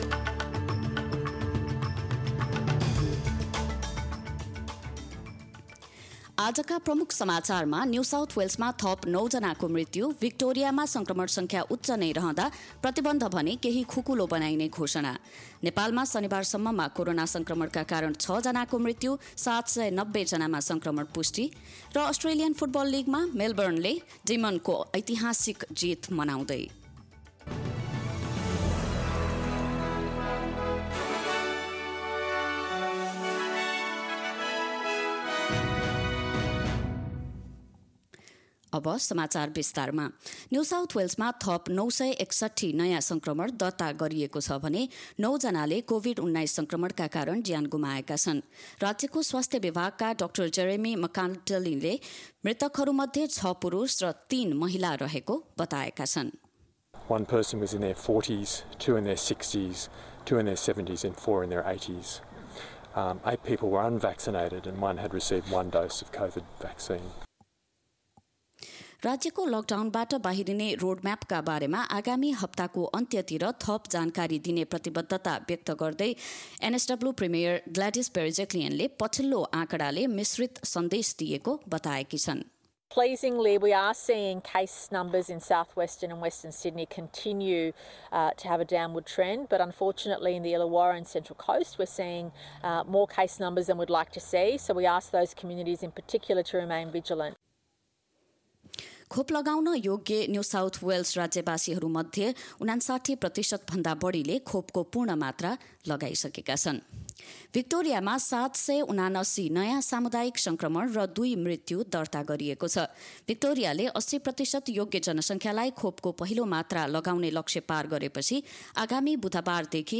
एसबीएस नेपाली अस्ट्रेलिया समाचार: आइतवार २६ सेप्टेम्बर २०२१
nepali_26.9.21_news.mp3